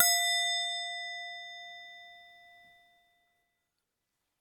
Goblet_F_Medium
bell chime ding dong goblet instrument ping sound effect free sound royalty free Music